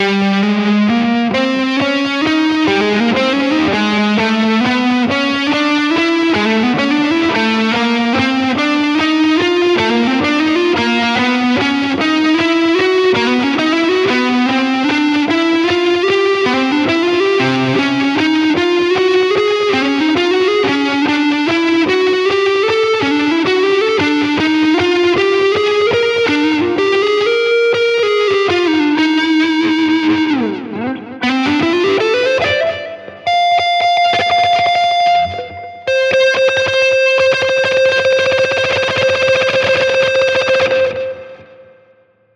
Hab jetzt irgendwas eingedudelt und das Ergebnis: Ich nehme keinen Unterschied wahr.